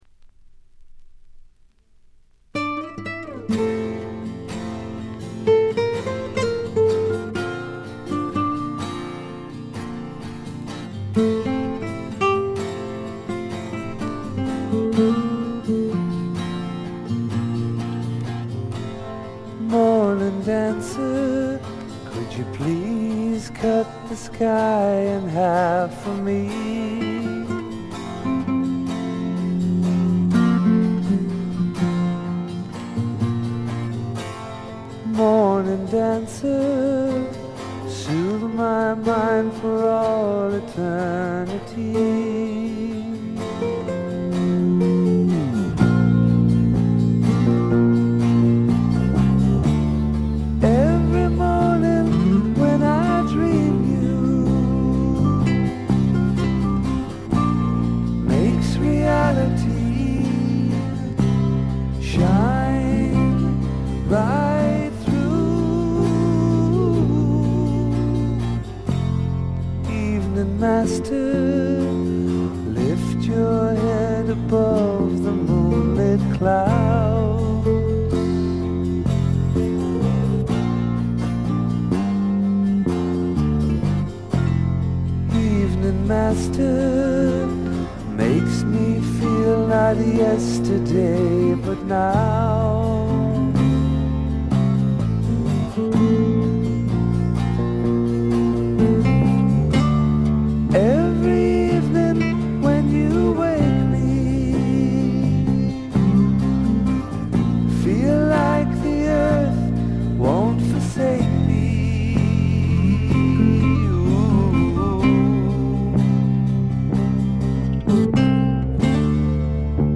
これ以外はわずかなノイズ感のみで良好に鑑賞できると思います。
試聴曲は現品からの取り込み音源です。